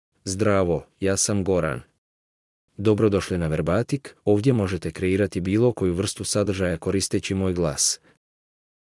MaleBosnian (Bosnia and Herzegovina)
GoranMale Bosnian AI voice
Goran is a male AI voice for Bosnian (Bosnia and Herzegovina).
Voice sample
Male
Goran delivers clear pronunciation with authentic Bosnia and Herzegovina Bosnian intonation, making your content sound professionally produced.